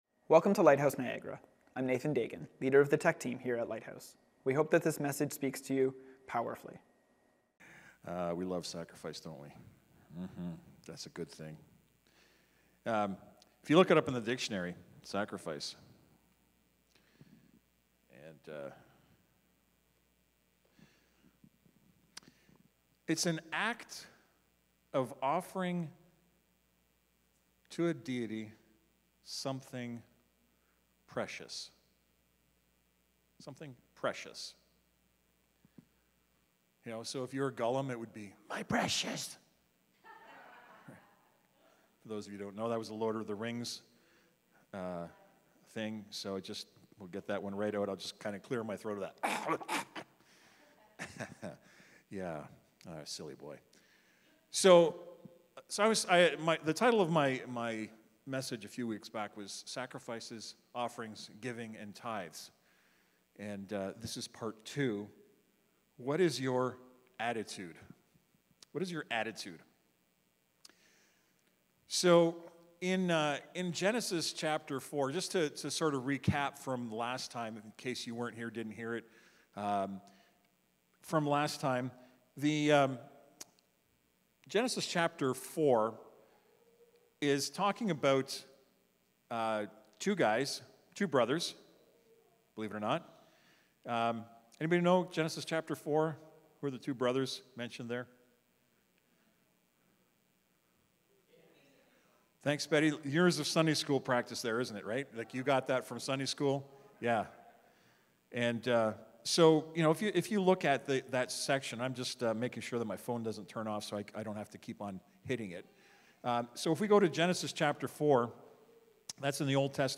Wednesday Night Bible Study